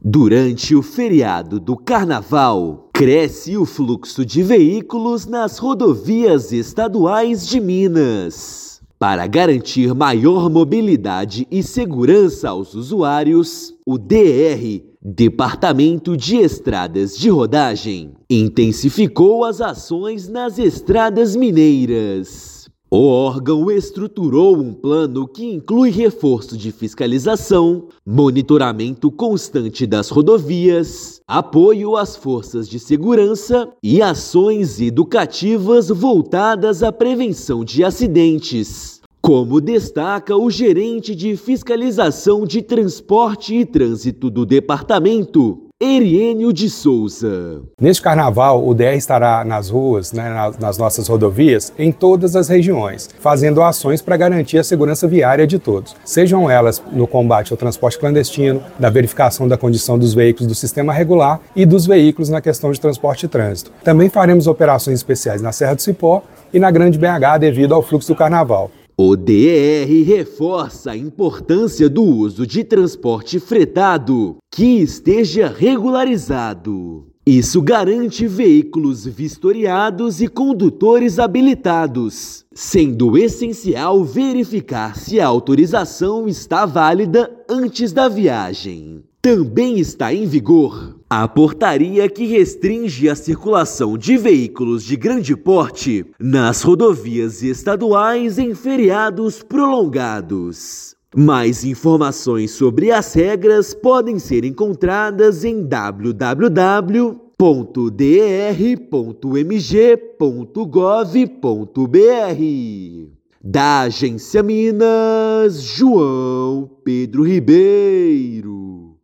A portaria que restringe o tráfego de veículos de grande porte já está em vigor. Ouça matéria de rádio.